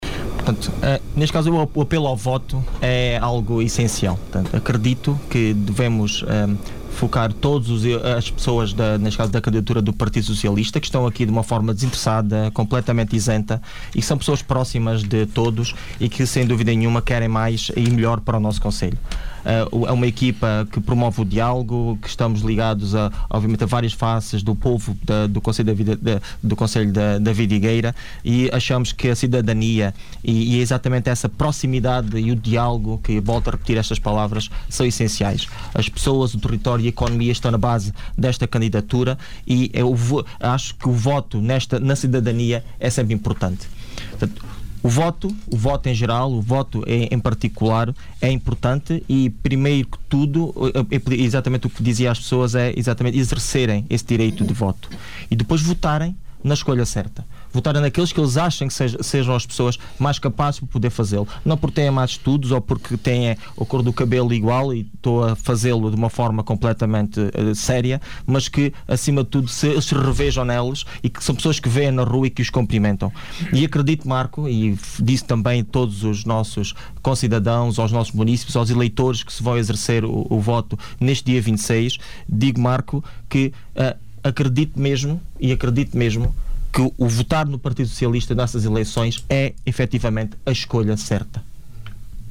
A Rádio Vidigueira promoveu o debate entre os cabeças de lista à Assembleia Municipal de Vidigueira, no âmbito das eleições autárquicas de 26 de Setembro.